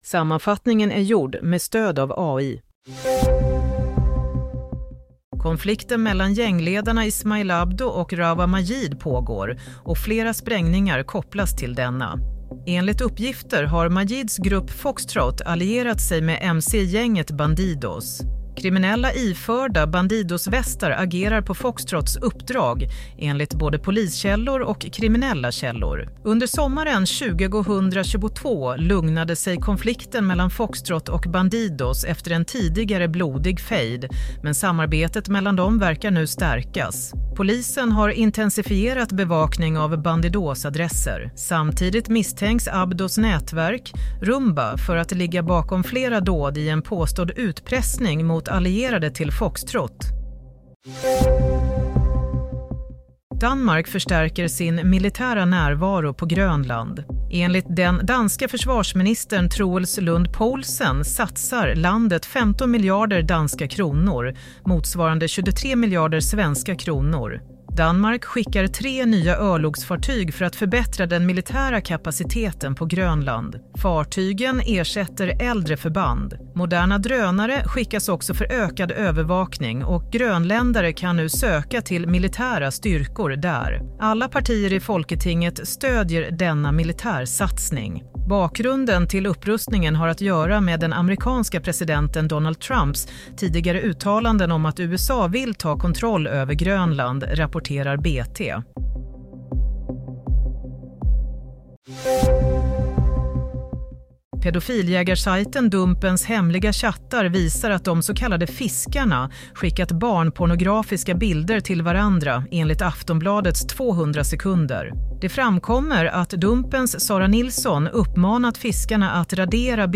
Play - Nyhetssammanfattning – 27 januari 22:00
Sammanfattningen av följande nyheter är gjord med stöd av AI.